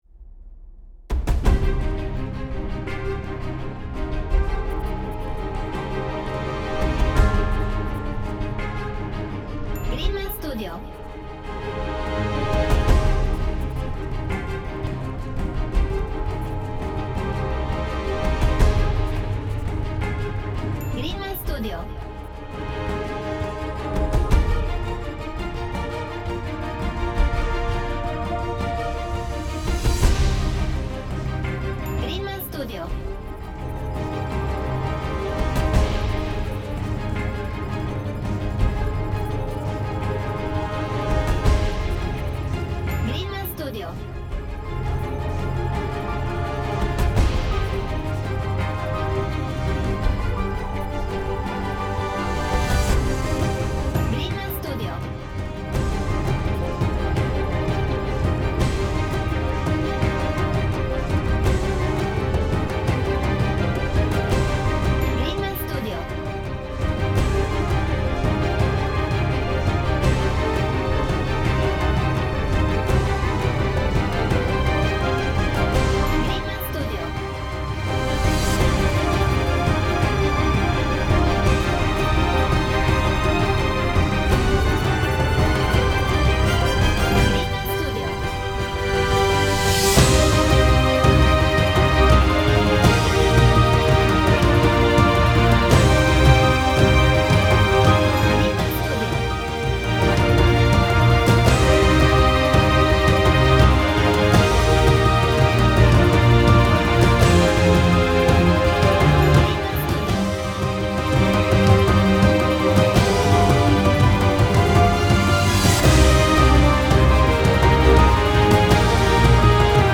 Orchestral/Cinematic
Epic